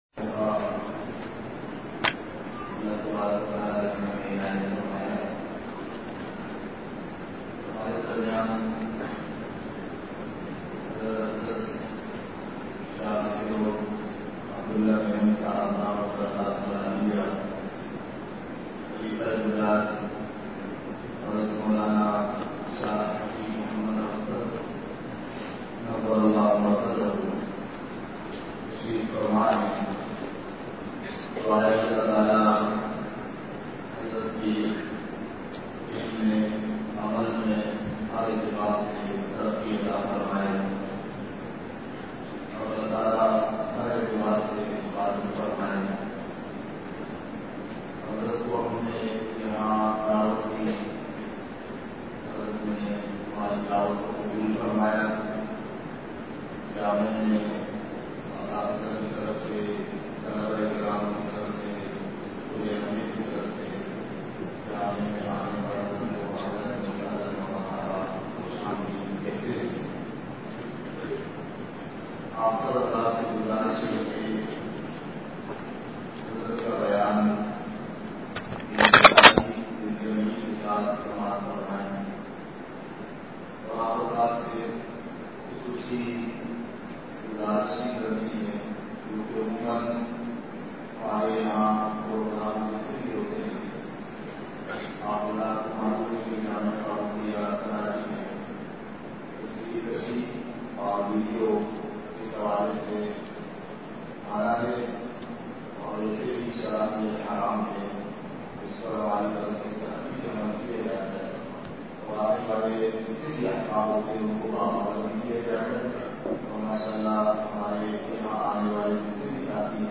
بیان مغرب ۹ نومبر ۲۵ء:جامعہ فاروقیہ فیز ۲